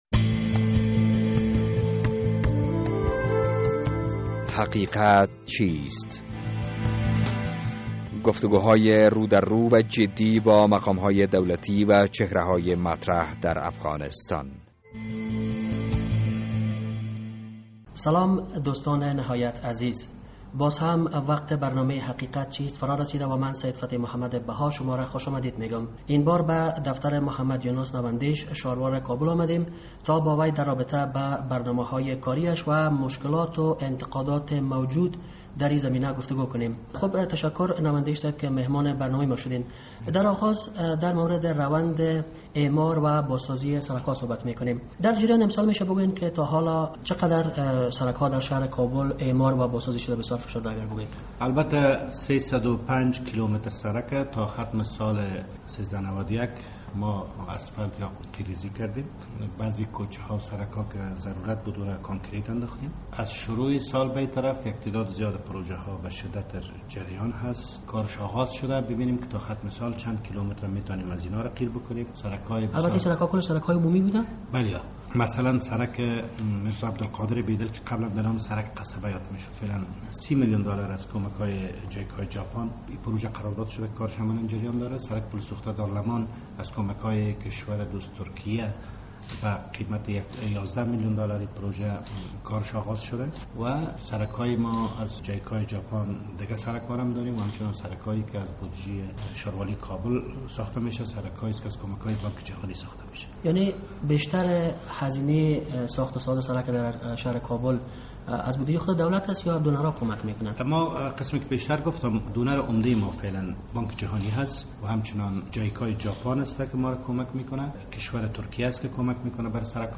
مهمان این هفتهء برنامهء حقیقت چیست، انجنیر محمد یونس نواندیش شاروال کابل است. با آقای نواندیش در رابطه اعمار و بازسازی سرک ها و نیز اعمار منازل رهایشی و ساختمان های تجارتی...